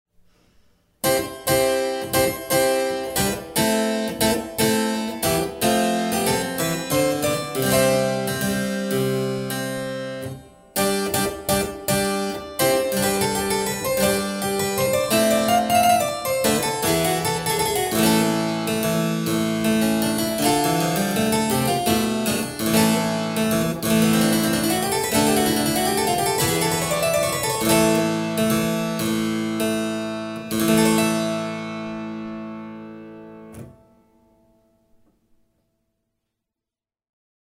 Clavicembalo
CLAVICEMBALO-AriaMarchesaSchiavonetta.mp3